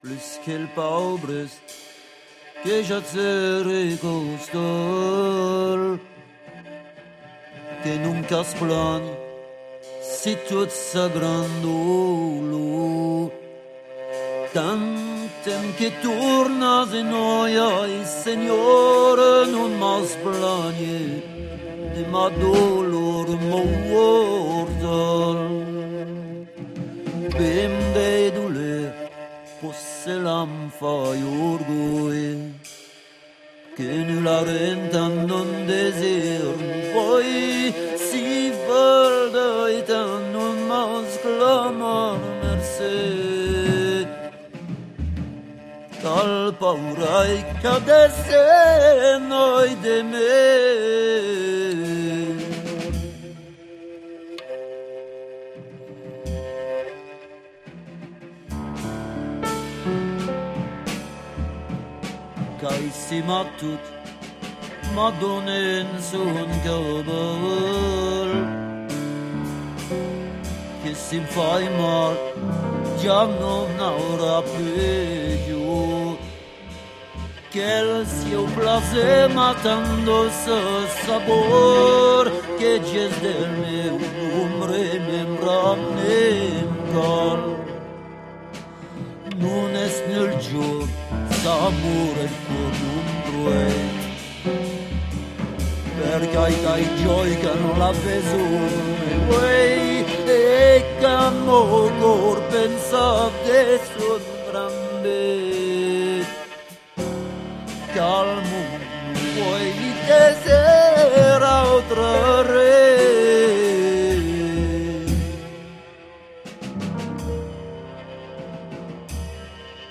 cansos dels trobadors /